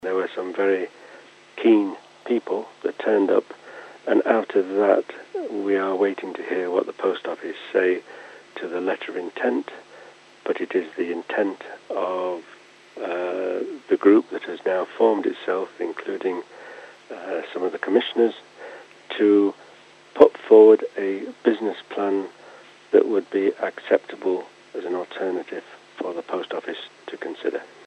Chairman of Ramsey Town Commissioners Nigel Malpass says more time is essential: